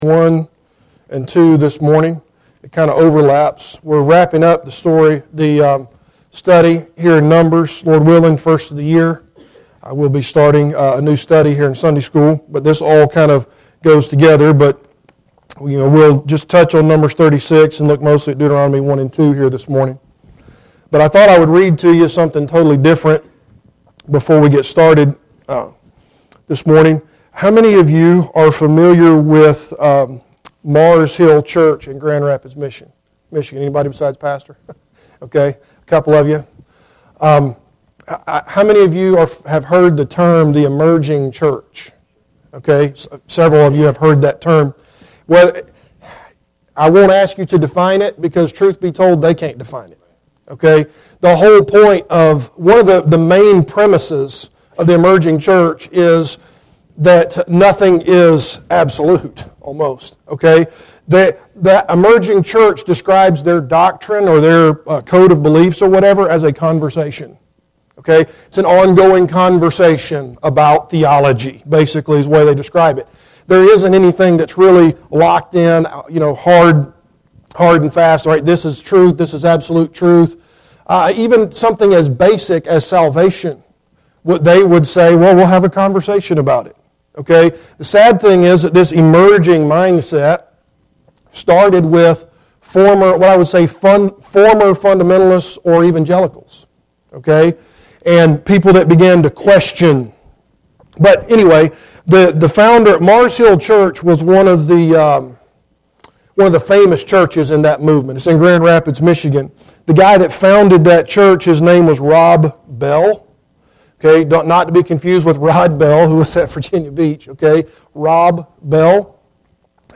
Passage: Numbers 36 Service Type: Adult Sunday School Class